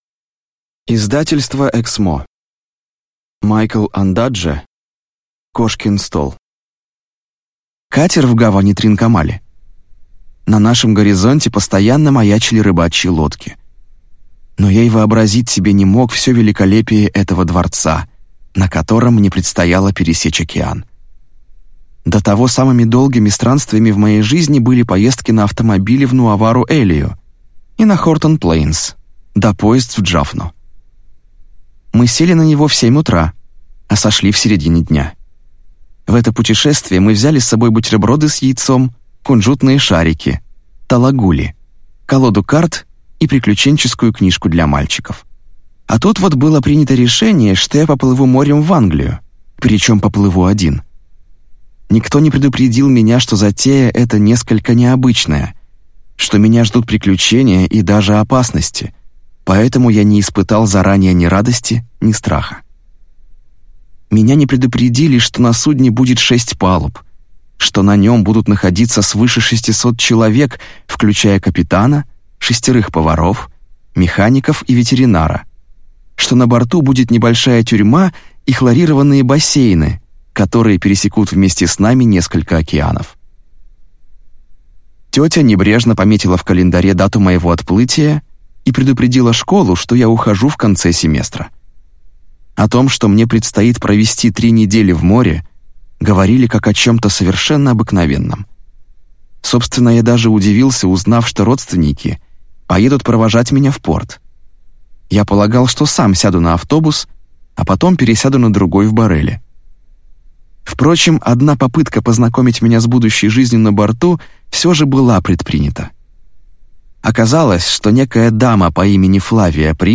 Аудиокнига Кошкин стол | Библиотека аудиокниг